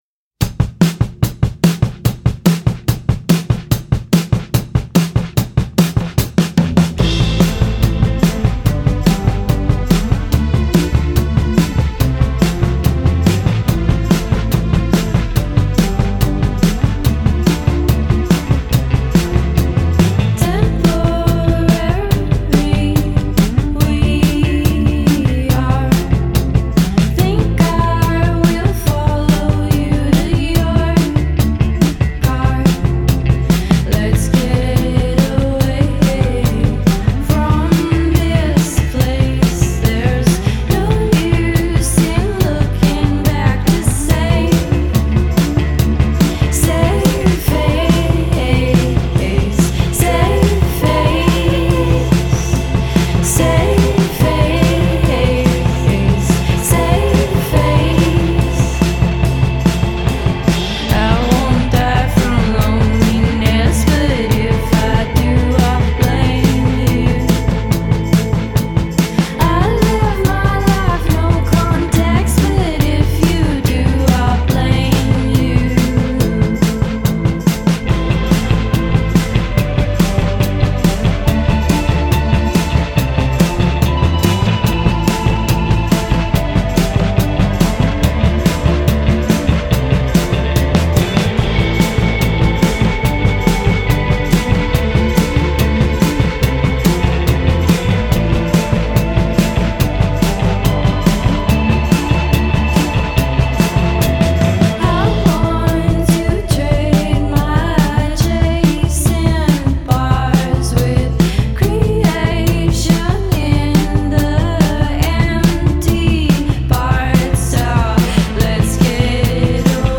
on flute
on guitar
on synth and guitar
on drums.
” which is lead by a driving beat, propulsive synths
angelic vocals